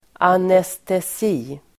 Uttal: [anestes'i:]